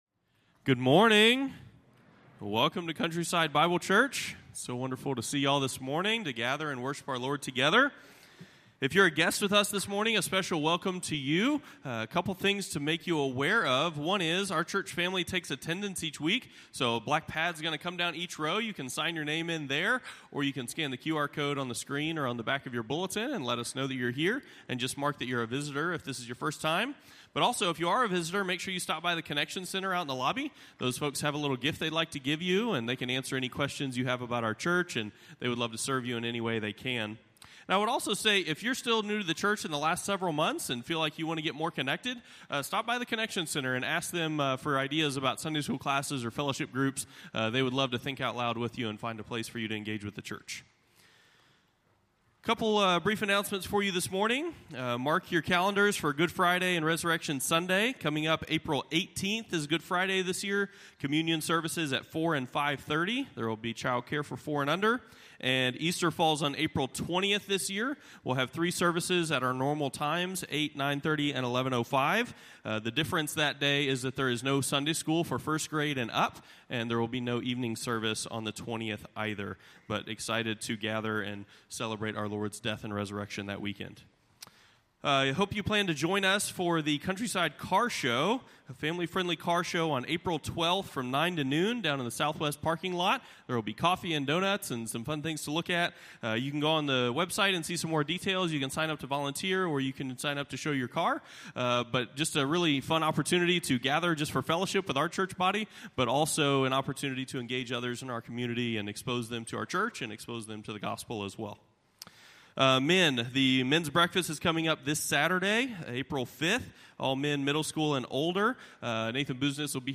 Morning Worship Service | Countryside Bible Church